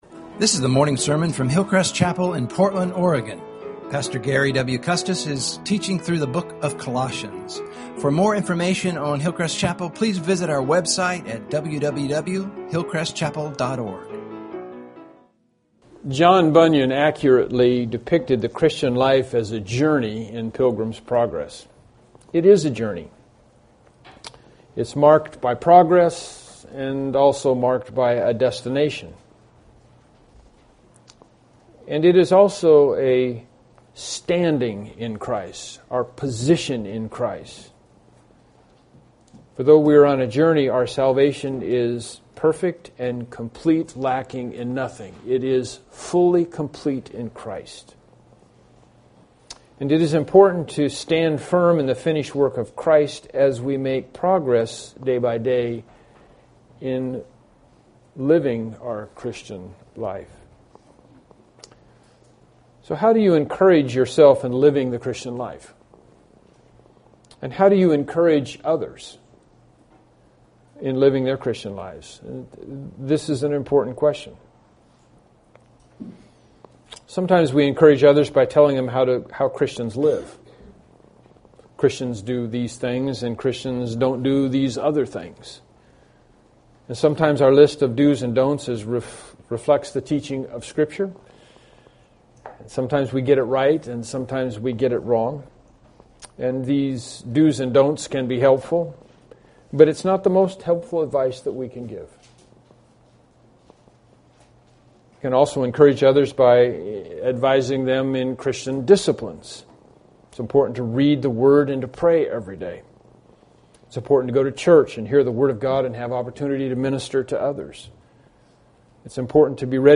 Bible Text: Colossians 2:1-5 | Preacher